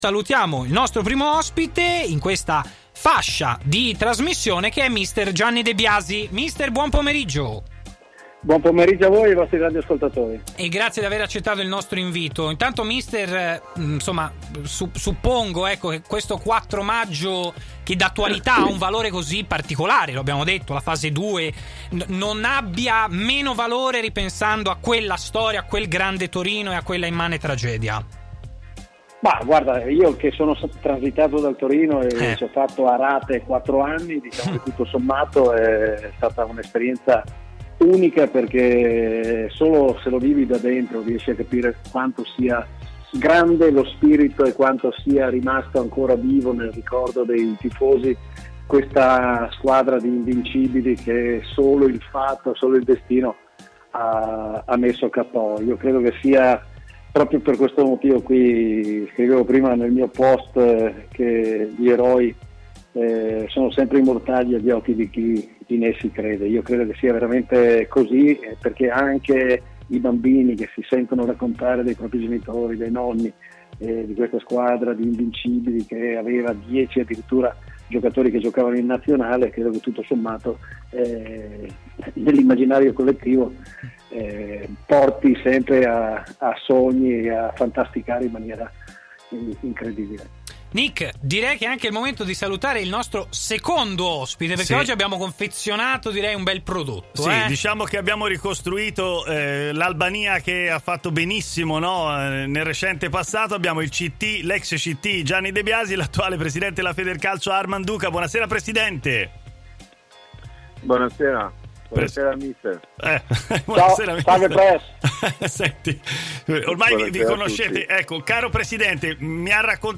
parlato intervenendo in diretta durante Stadio Aperto, trasmissione in onda su TMW...